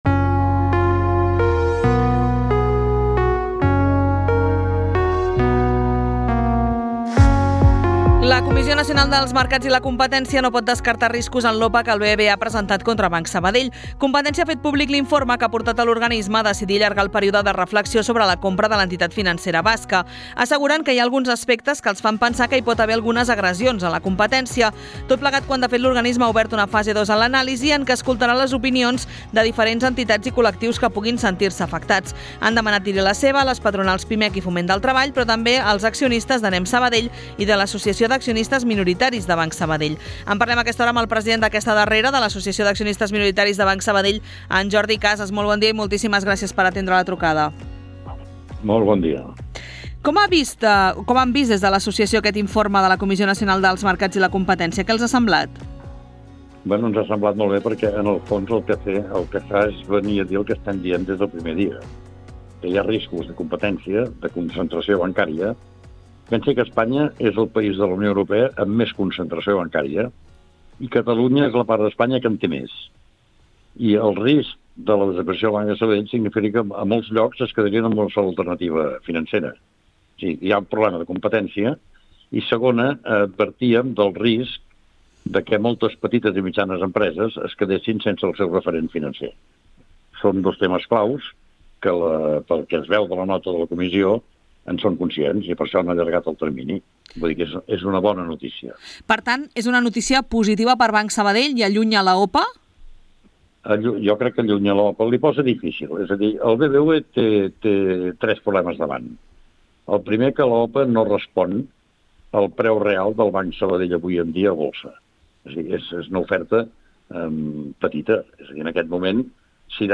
En una entrevista al Fils de Ciutat de RàdioSabadell